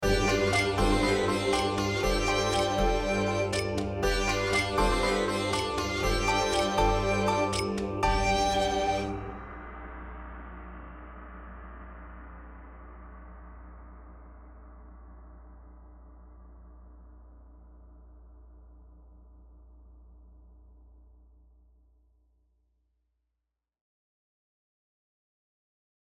Plays short end of the track